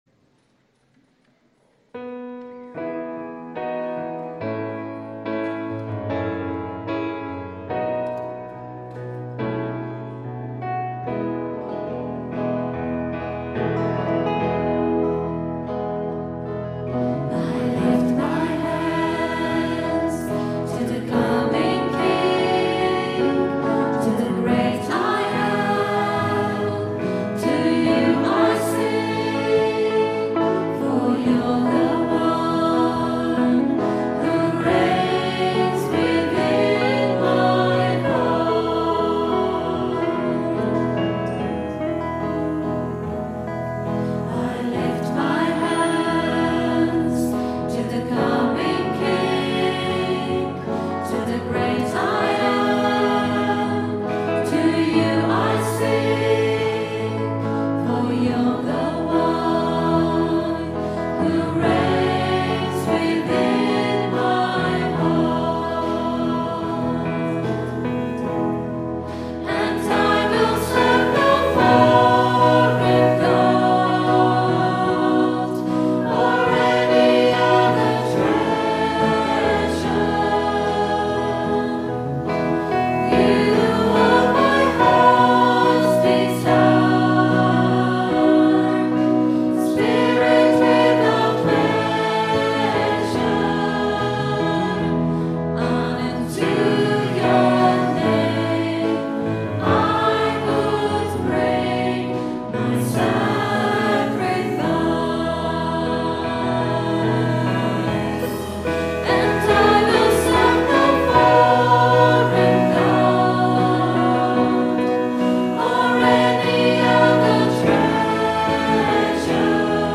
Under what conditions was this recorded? Recorded on a Zoom H4 digital stereo recorder at 10am mass, 26th September 2010.